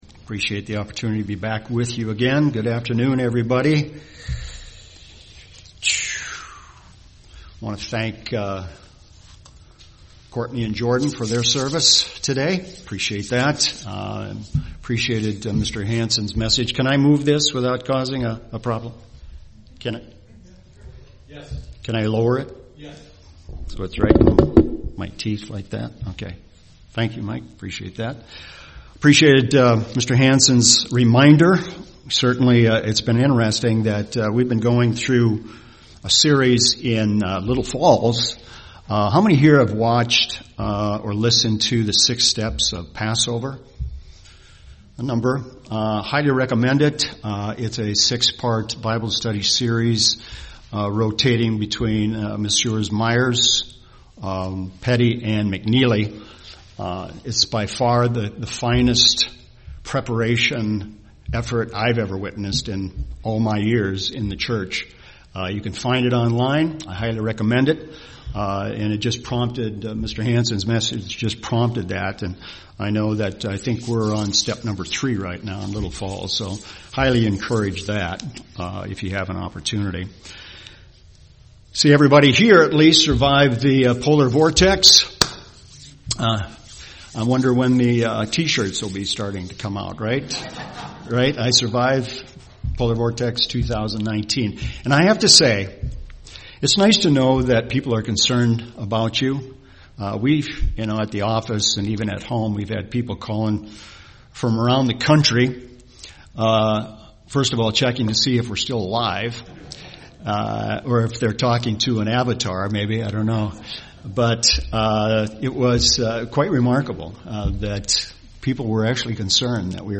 Given in Twin Cities, MN
UCG Sermon angels and demons Angels Studying the bible?